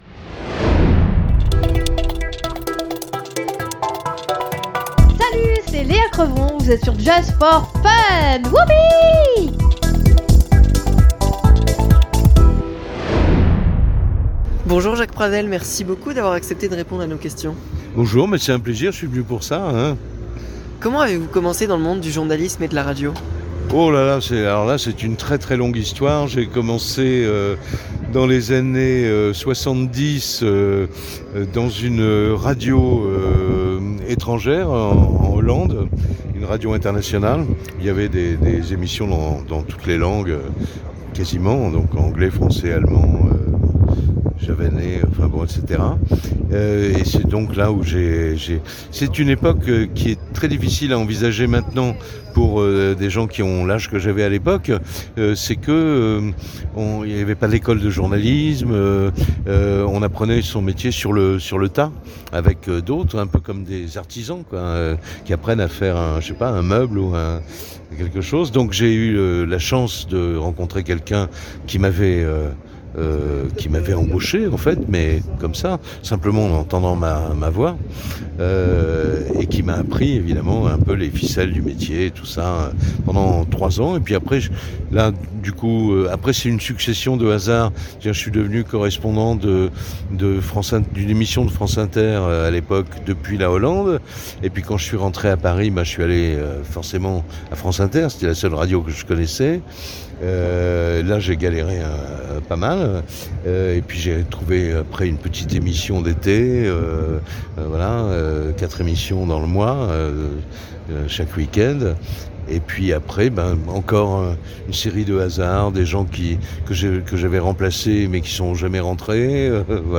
Interview de Jacques Pradel
Le journaliste Jacques Pradel a accepté de repondre à nos questions lors du salon du livre du Mans.